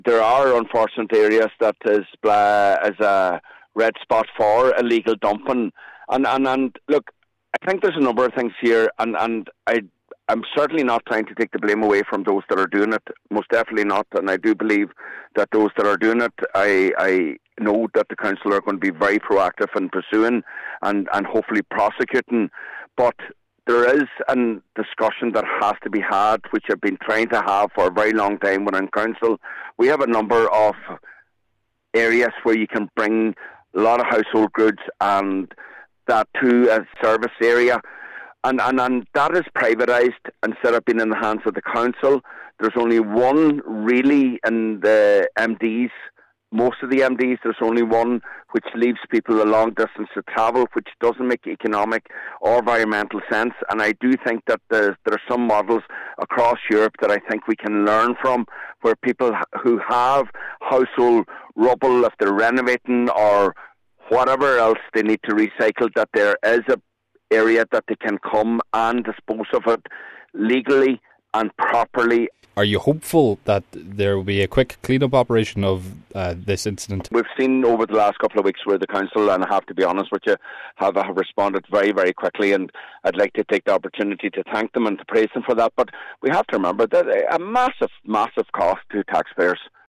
Cllr Mac Giolla Easbuig says littering is never acceptable, and it is particularly shameful given the scenic beauty of the west of the county: